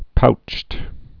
(poucht)